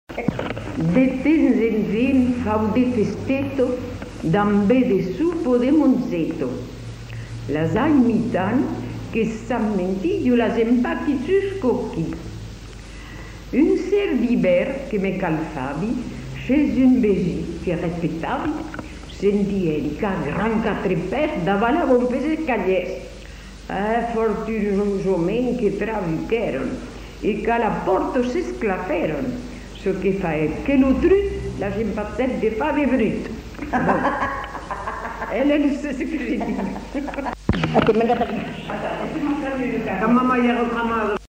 Aire culturelle : Haut-Agenais
Genre : conte-légende-récit
Effectif : 1
Type de voix : voix de femme
Production du son : récité
Classification : monologue